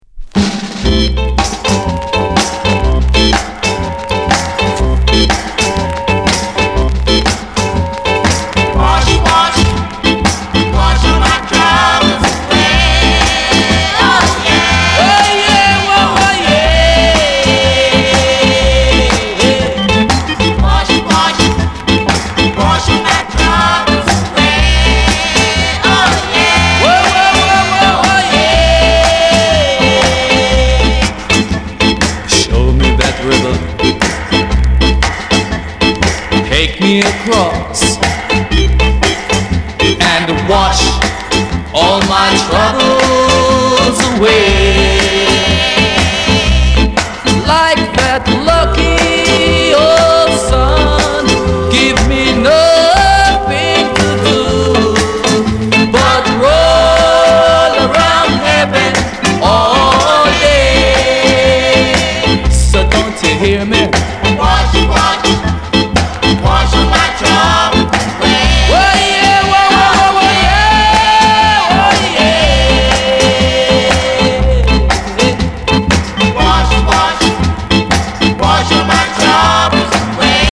Genre: Ska